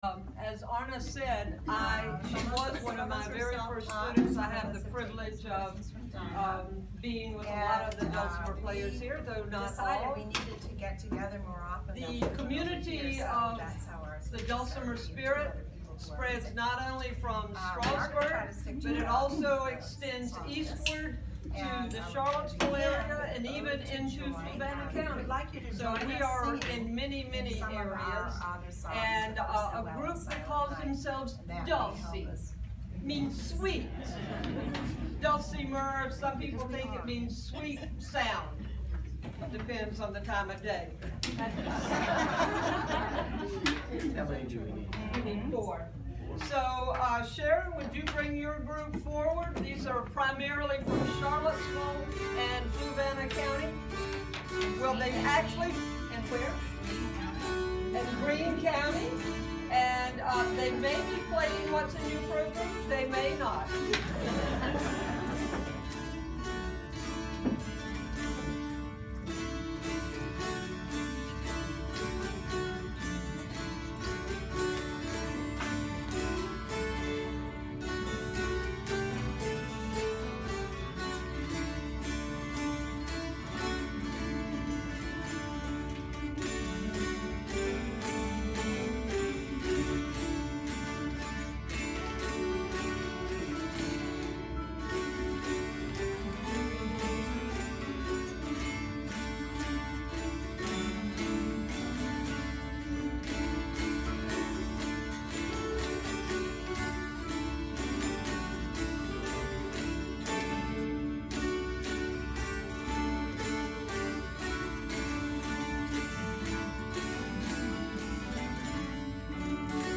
Enjoy these photos and audio clips of our December 16 Holiday Concert at the Fishersville Library!
MANY VOICES OF THE MOUNTAIN DULCIMER concluding with a SING-A-LONG
2017-fishersville-christmas-concert-audio.mp3